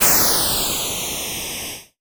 ChipTune Cymbal 01.wav